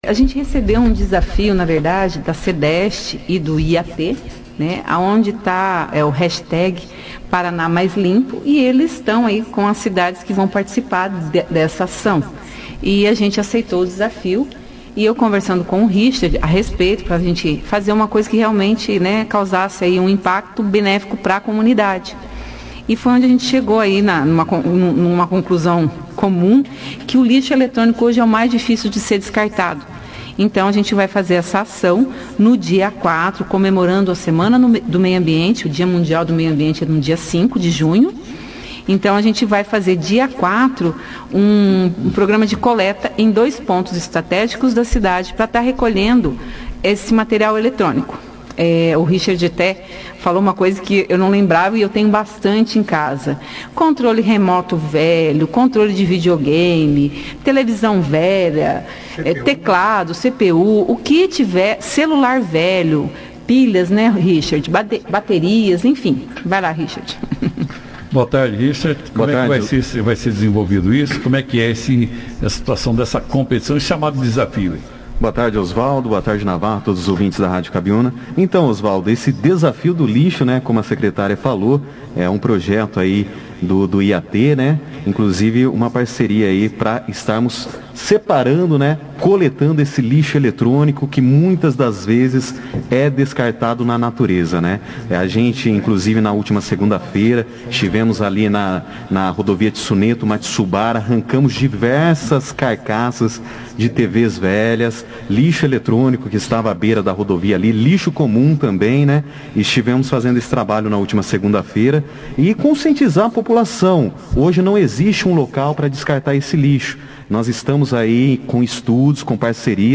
participaram da 2ª edição do jornal Operação Cidade, desta quarta-feira, 02/06, falando sobre este evento e também sobre a coleta seletiva do lixo, inclusive neste feriado desta quinta-feira, 03, de Corpus Christi e da coleta de galhos.